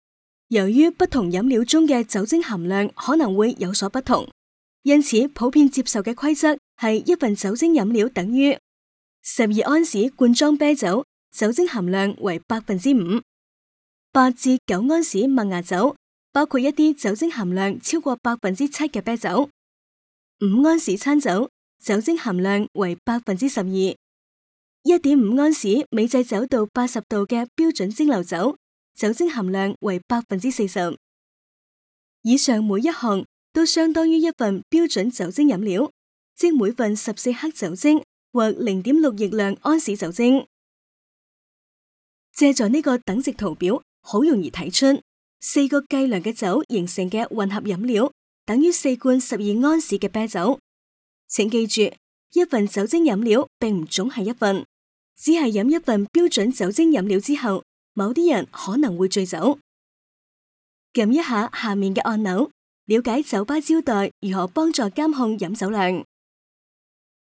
Cantonese_Female_003VoiceArtist_10Hours_High_Quality_Voice_Dataset